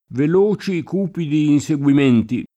cupido [k2pido] agg.